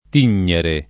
tignere [ t & n’n’ere ]